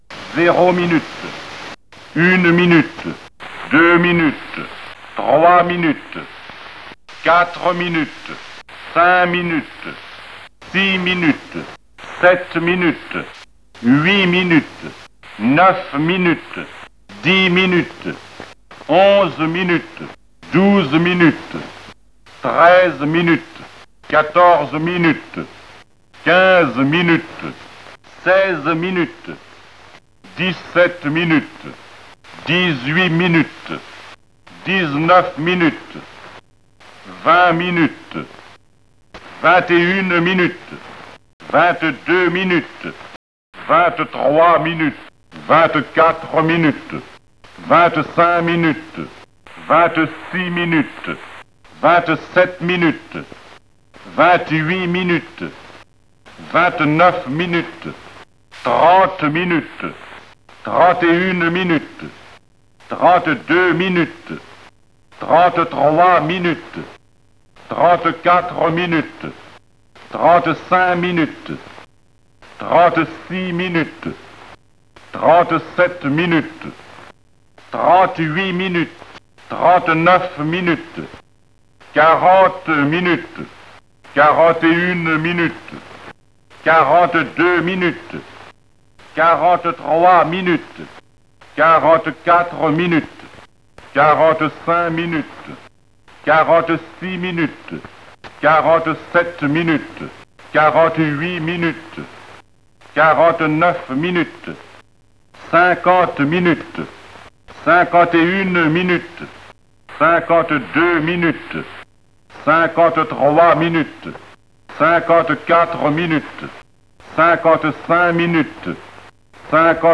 Accessible en composant un numéro dédié, elle énonce l’heure avec une précision à la seconde près, rythmée par un signal sonore.